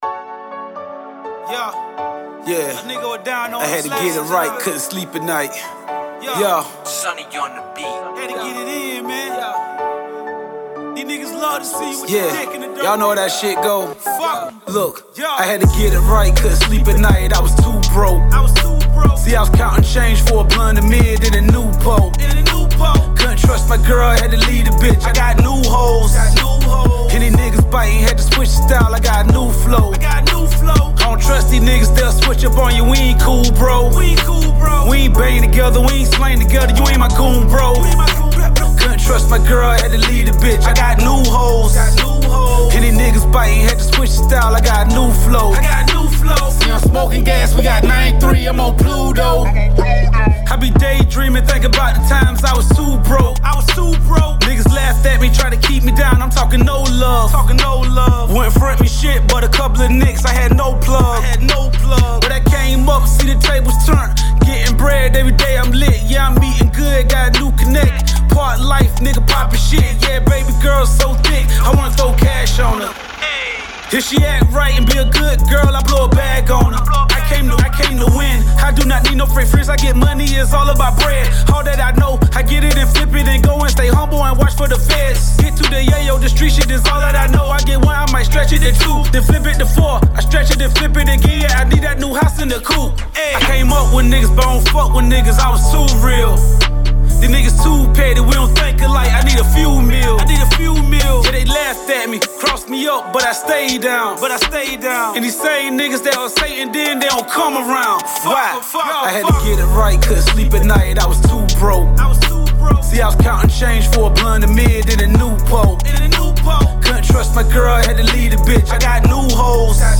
Hiphop
Description : Strip Club BaNGER